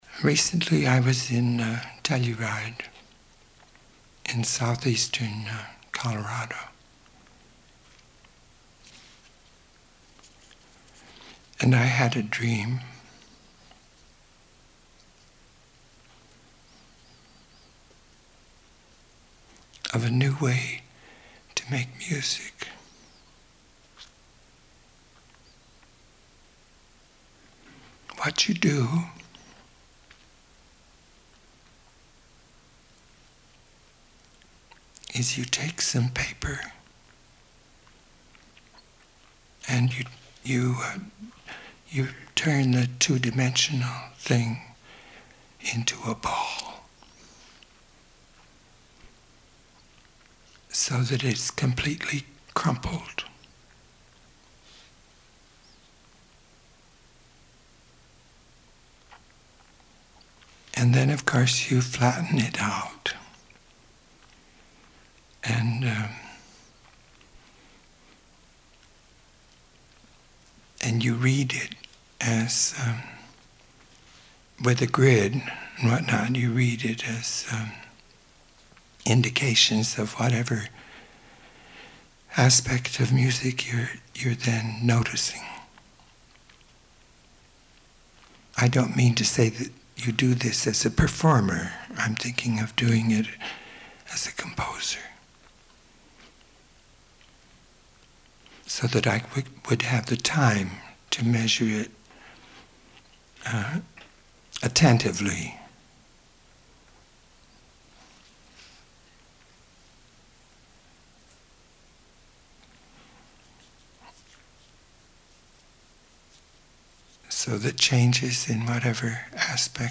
Cage himself only put on How to Get Started once, at an international conference on sound design at George Lucas’ Skywalker Ranch on August 31, 1989.
On went a tape recorder, and he began speaking improvisationally about the first idea. Then he flipped to the next card, and as he talked about its idea, the recording of the first one played in the background. He continued with this procedure until, by the tenth idea on the tenth card, he had his impromptu speeches on all nine previous ideas playing simultaneously behind him.